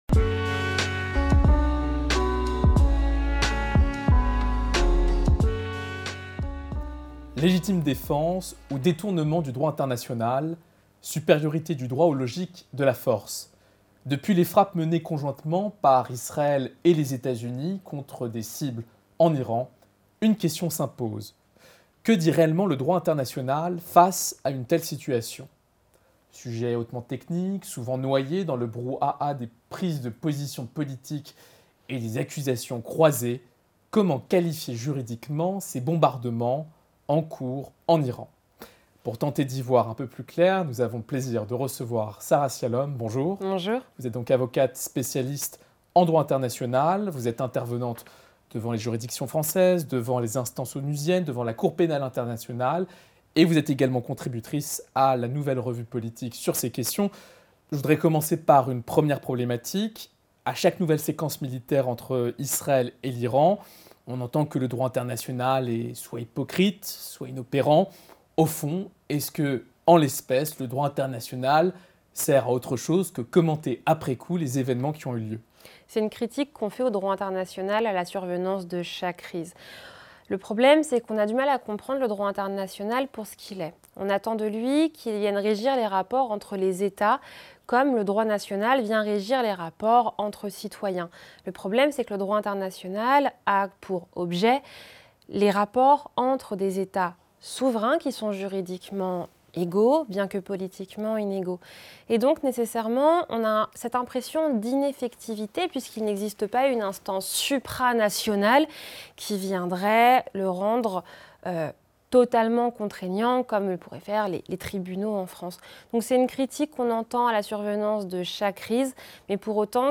25 min Entretien L’Iran instrumentalise le droit international Iran